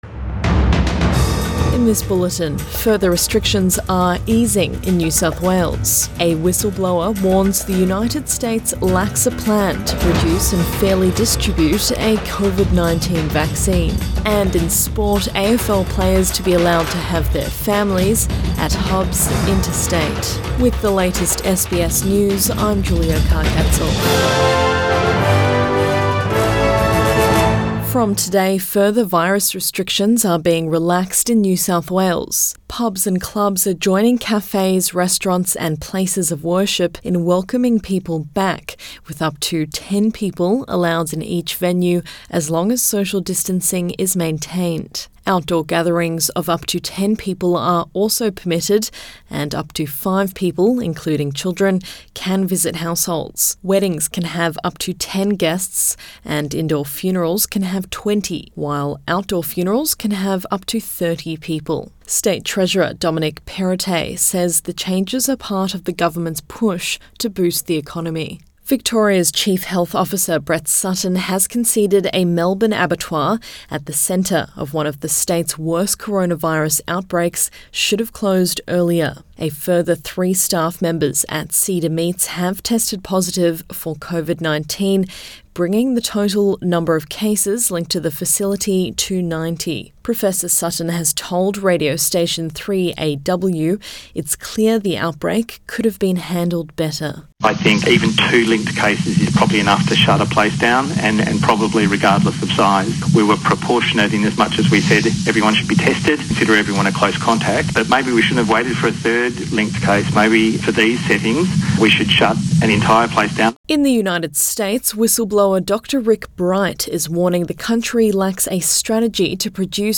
AM bulletin 15 May 2020